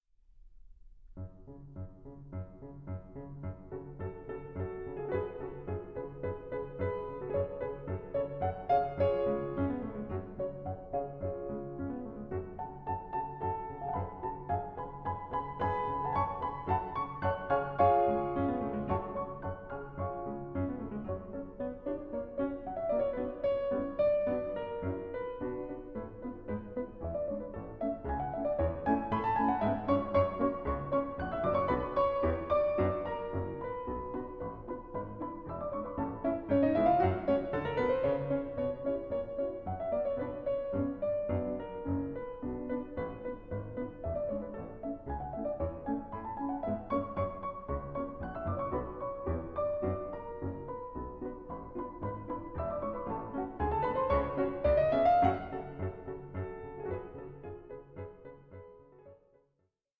Suite for Piano Duo